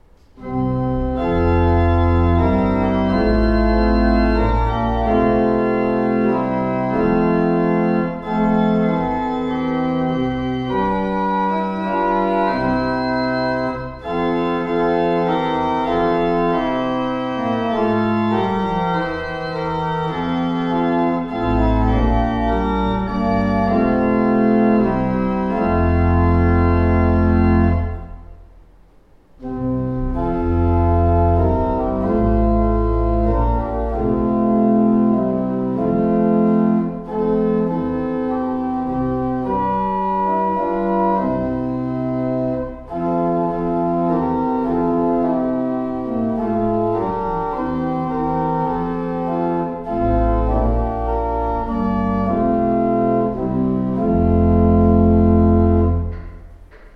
Organist Gloucestershire, UK